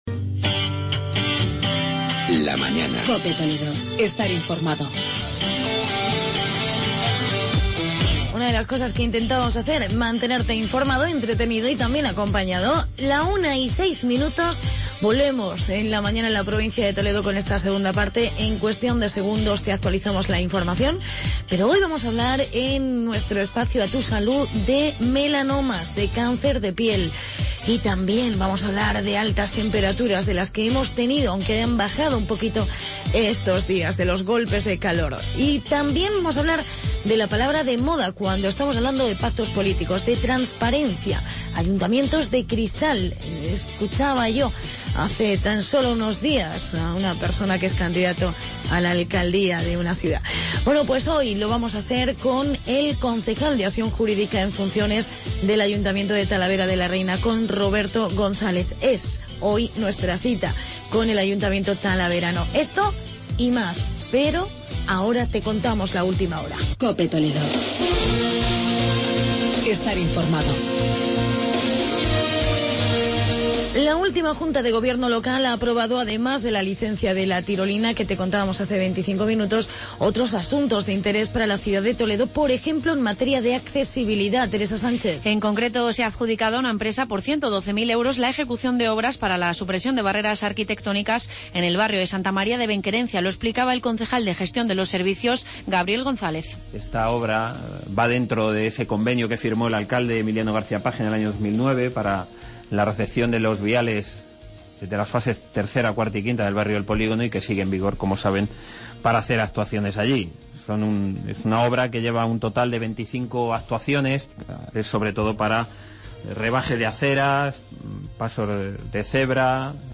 Hablamos con el concejal Roberto González del Portal de Transparencia y en "A Tu Salud" del melanoma.